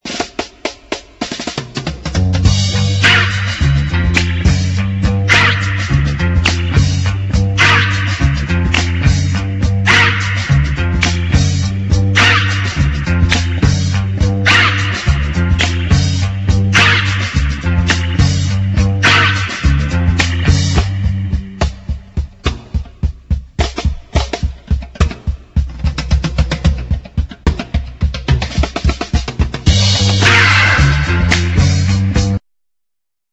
sexy medium instr.